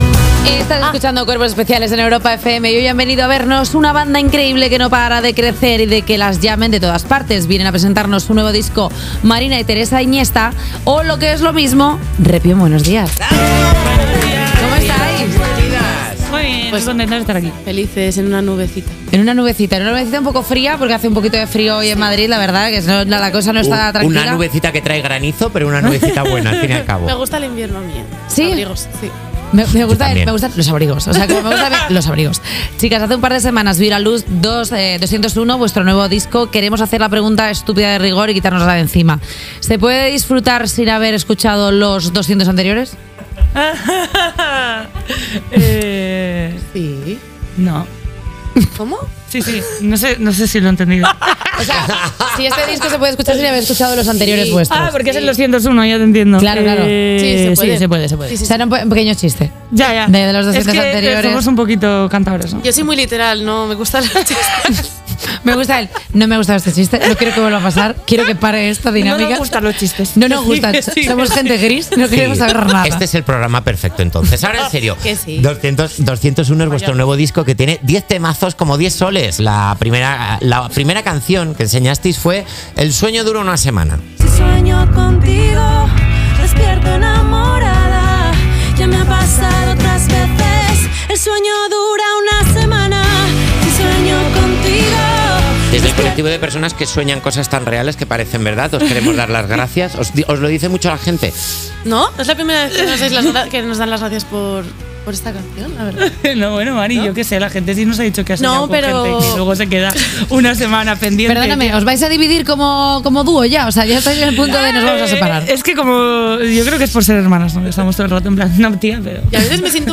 La entrevista de Repion en 'Cuerpos especiales'